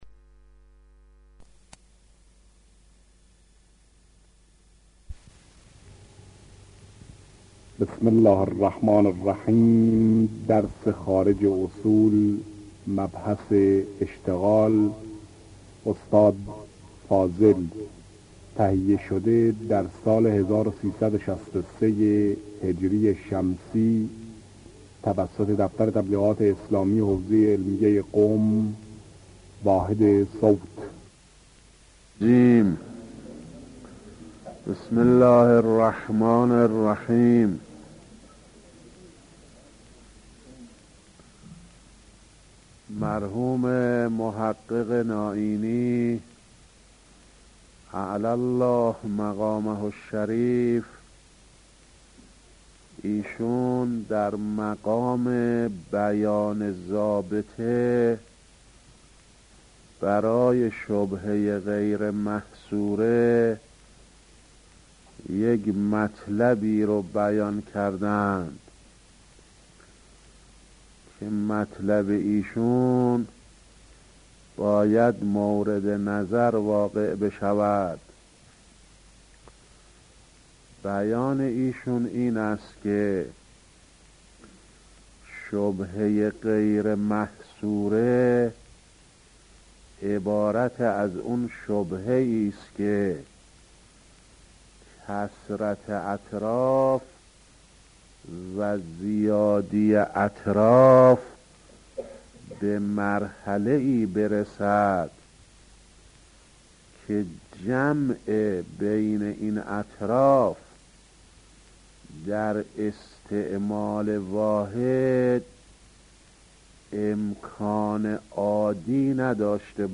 آيت الله فاضل لنکراني - خارج اصول | مرجع دانلود دروس صوتی حوزه علمیه دفتر تبلیغات اسلامی قم- بیان